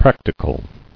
[prac·ti·cal]